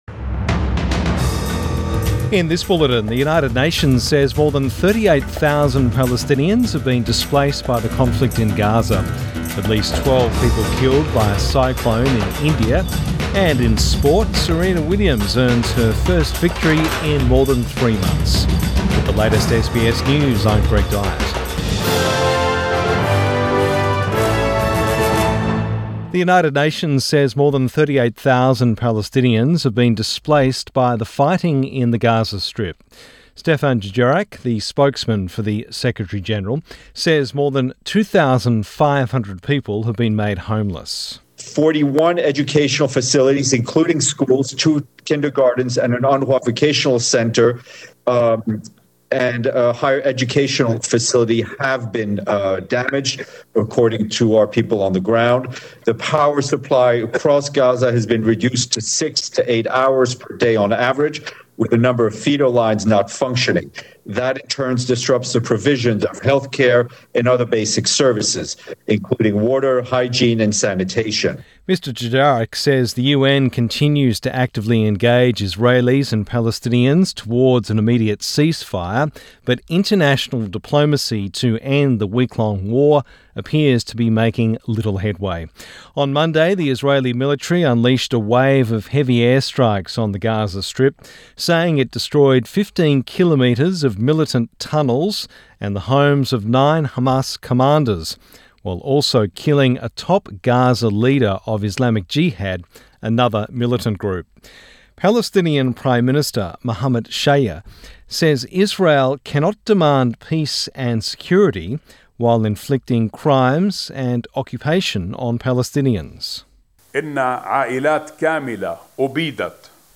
AM bulletin 18 May 2021